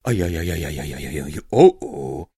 На этой странице собрана коллекция звуков, сопровождающих мелкие проблемы и досадные недоразумения.